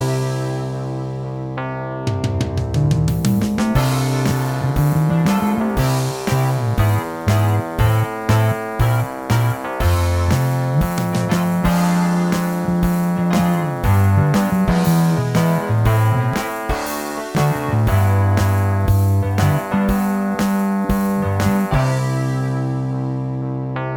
Minus Guitars Rock 2:48 Buy £1.50